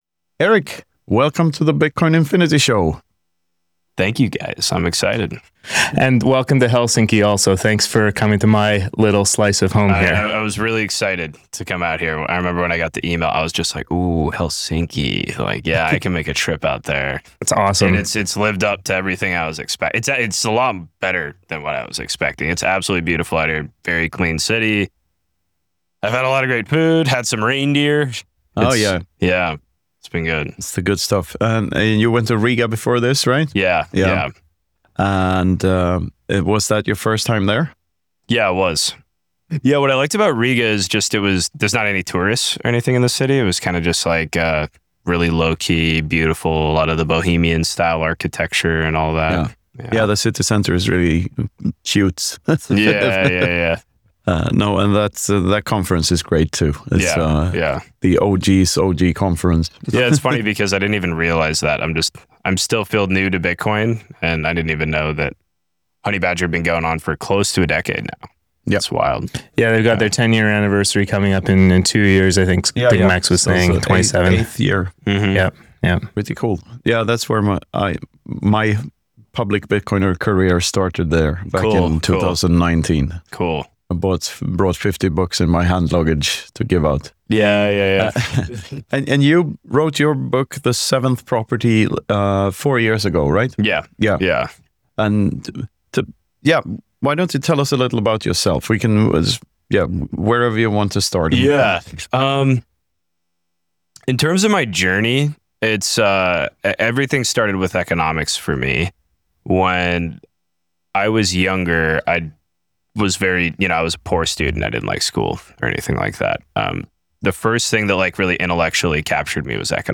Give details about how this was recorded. live and in person from Helsinki just before the BTC HEL conference.